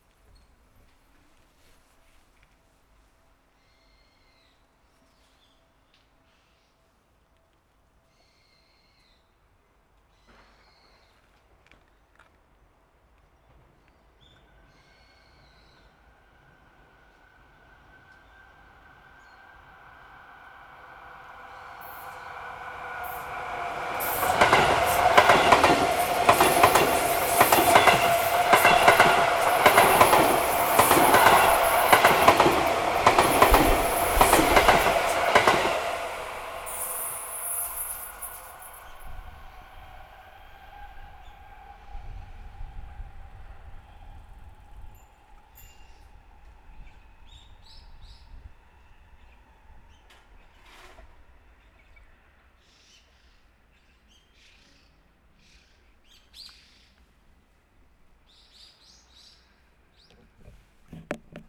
上り電車通過。
H2essential MS内蔵マイク指向性90°＋
ZOOM　ヘアリーウィンドスクリーン WSH-2e